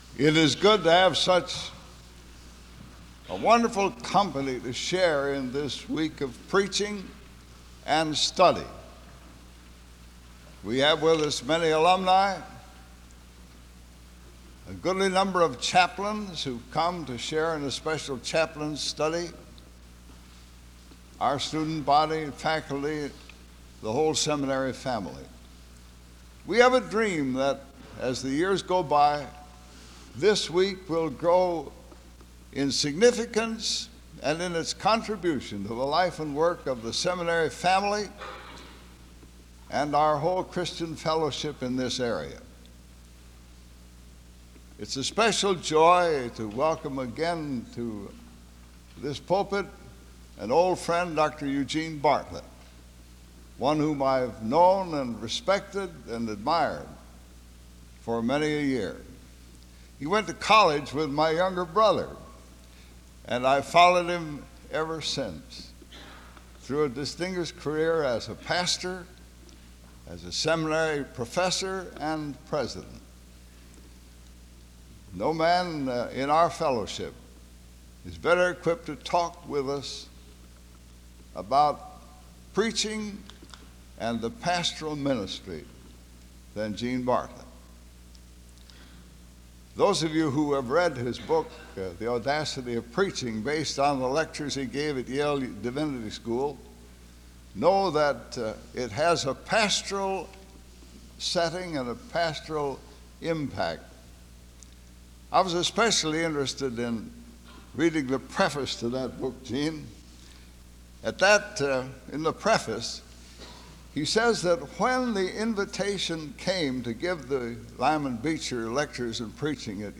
Home SEBTS Adams Lecture